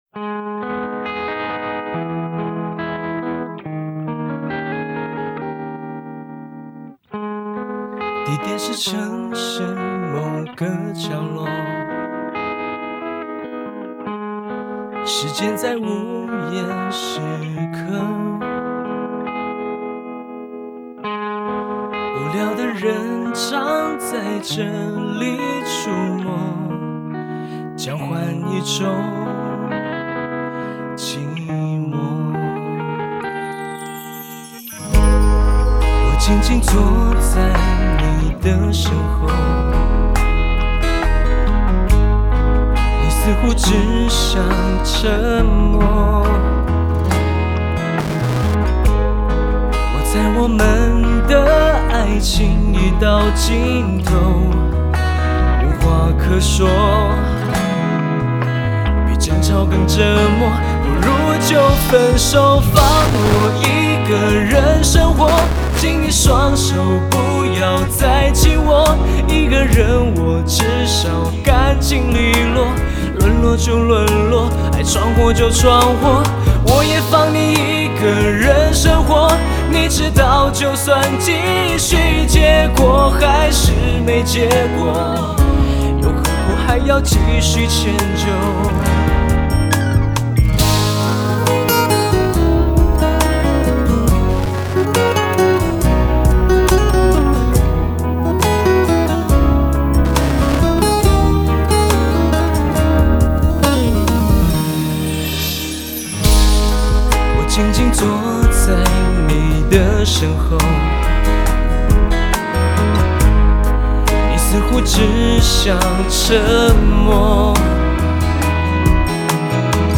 流行 收藏 下载